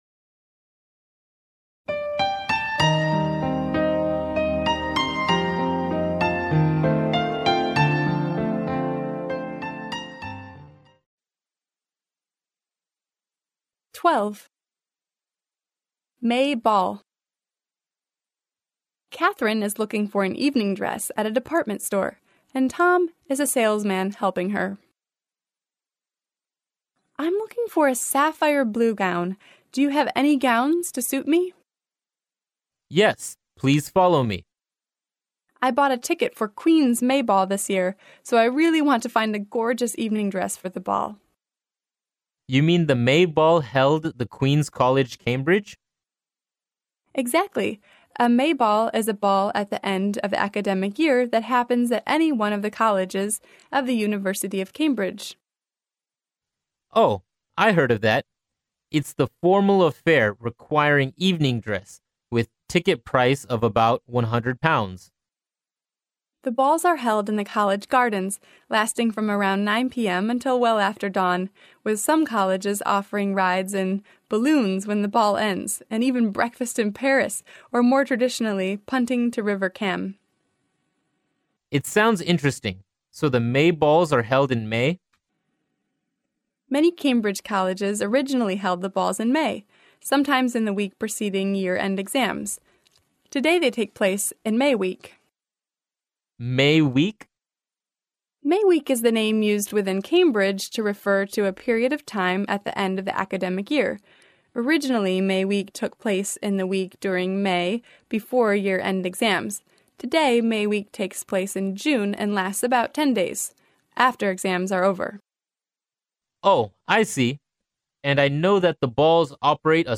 剑桥大学校园英语情景对话12：炫彩五月舞会（mp3+中英）